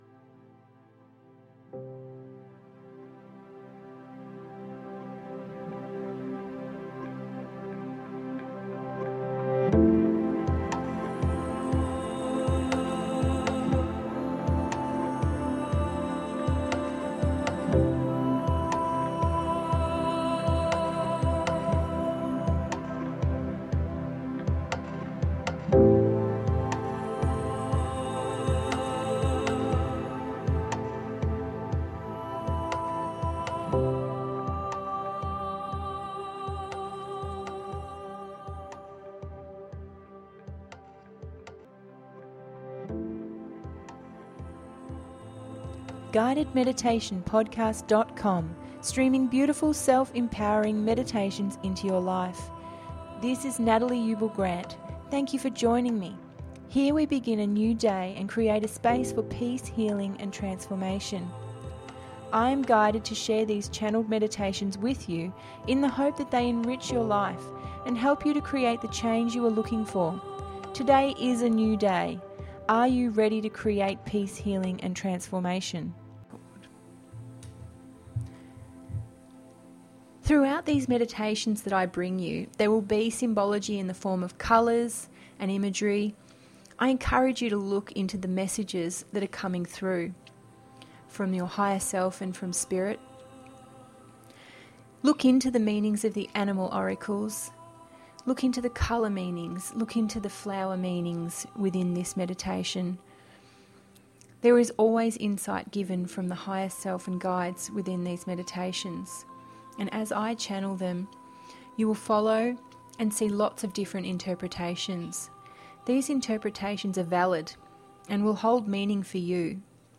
This relaxing walk into the flower garden will allow you to let go of mind chatter and the pressures of life.
049-flower-garden-animal-guide.mp3